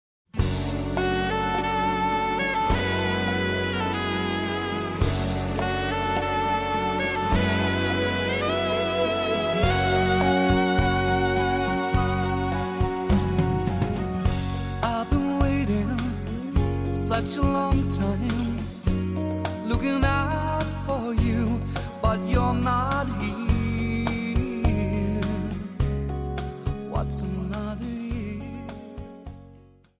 Rumba